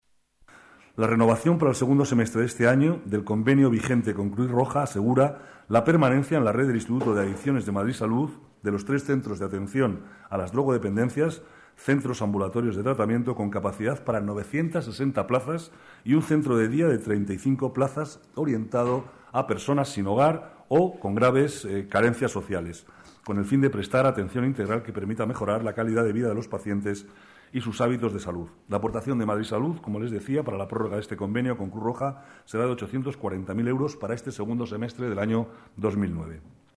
Nueva ventana:Declaraciones de Manuel Cobo sobre el convenio con Cruz Roja